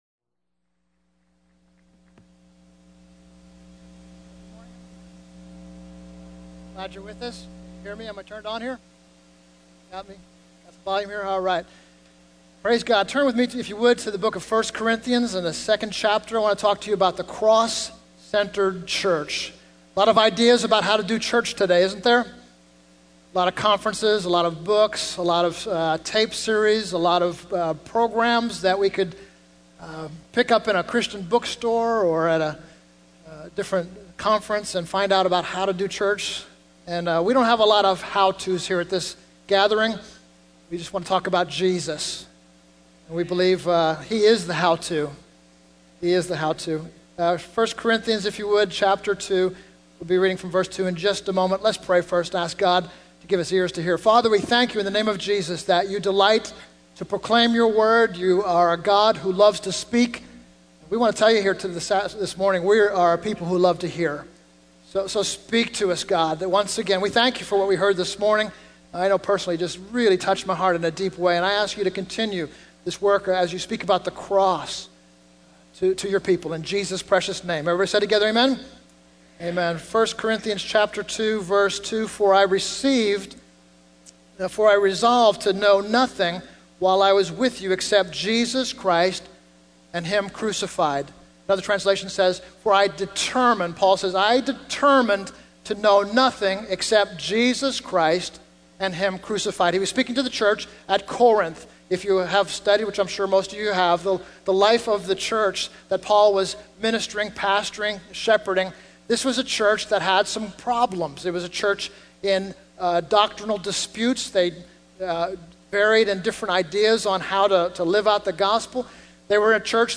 The Cross-Centered Church (Birmingham Conference)